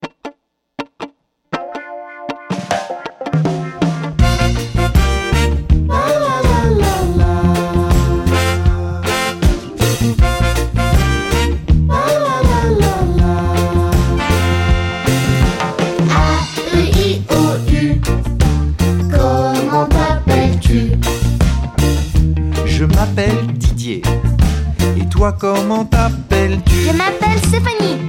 This cheerful reggae song teaches vowels